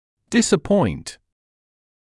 [ˌdɪsə’pɔɪnt][ˌдисэ’пойнт]разочаровывать, огорчать